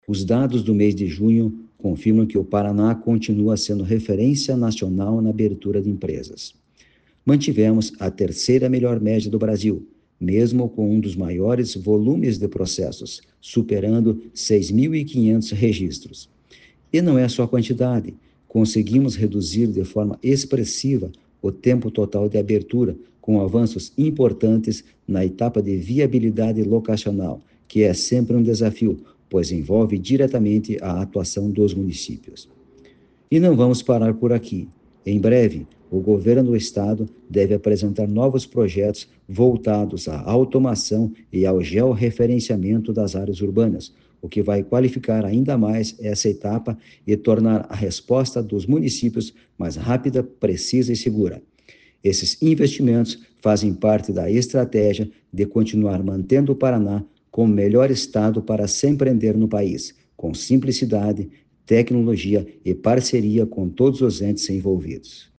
Sonora do presidente da Jucepar, Marcos Rigoni, sobre o tempo de abertura de empresas no Paraná